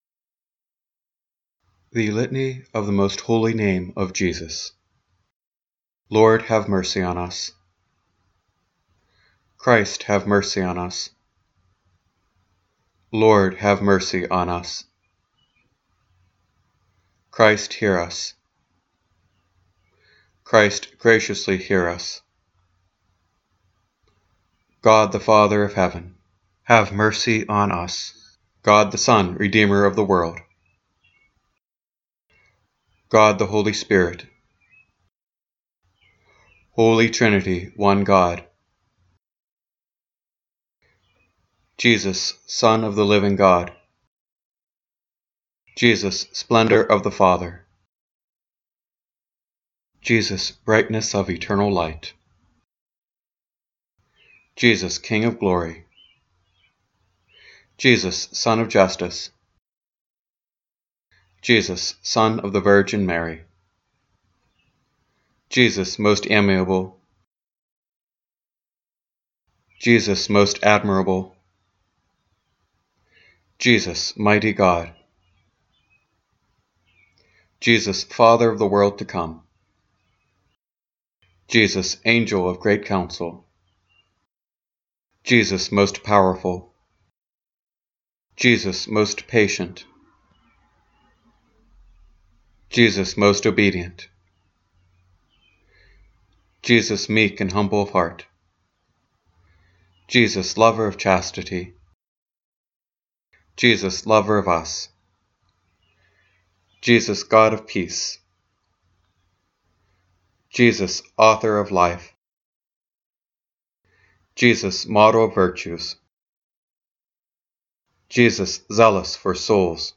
The invocations of the litanies are recorded and the response is left open for the listener to participate.
litany-of-the-most-holy-name-of-jesus.mp3